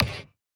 soft-sliderwhistle.wav